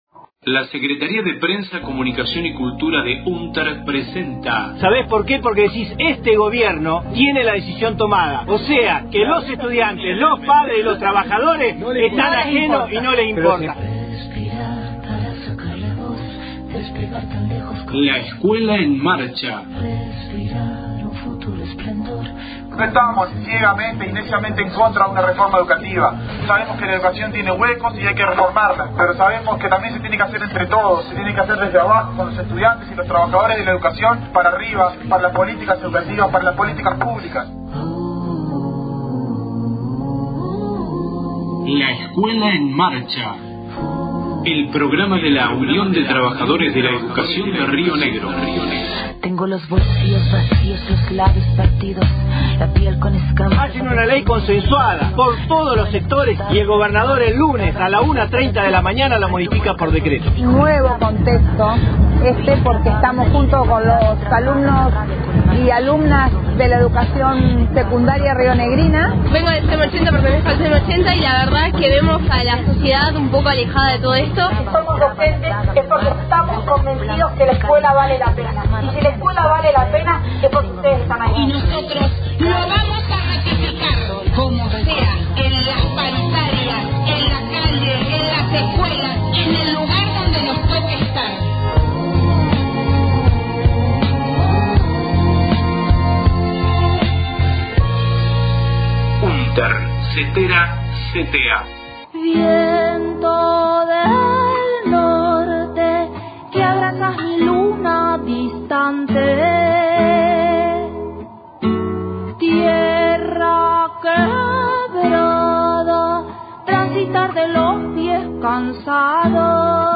Audio de marcha en Viedma el 9 de agosto, contra la instalación de una plata nuclear: